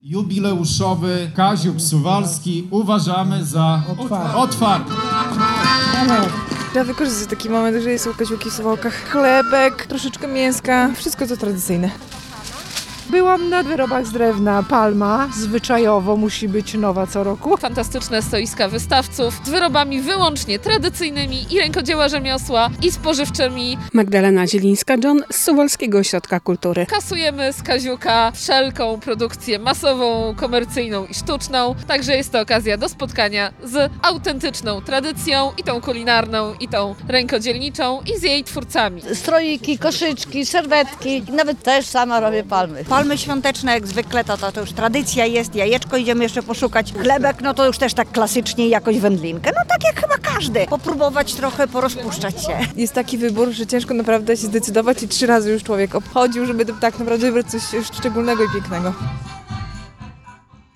Jarmark Kaziukowy w Suwałkach. Byli lokalni wystawcy i zespoły ludowe - relacja